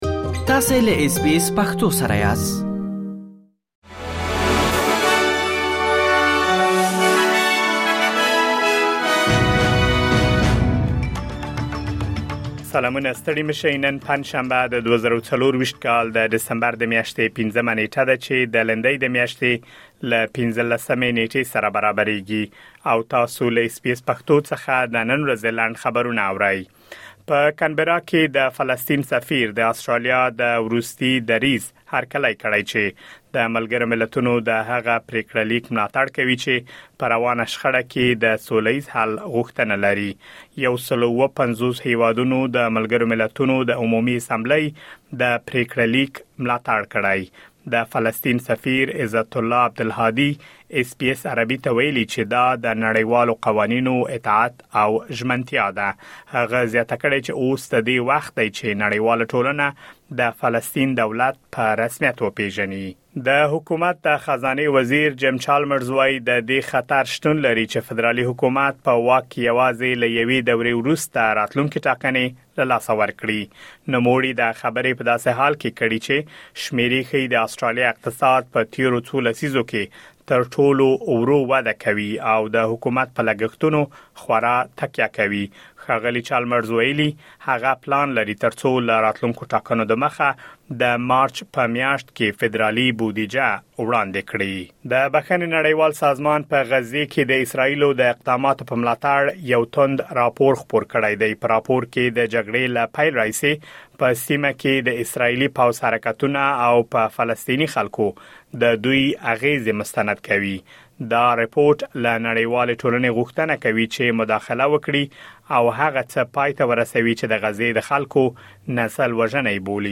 د اس بي اس پښتو د نن ورځې لنډ خبرونه |۵ ډسمبر ۲۰۲۴